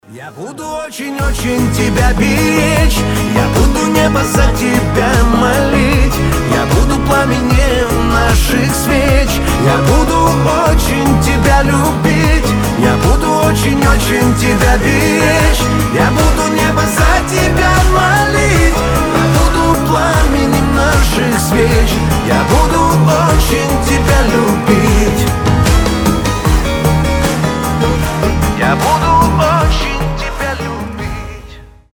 Танцевальные рингтоны
Рингтоны шансон , Гитара
Мужской голос , Душевные
Поп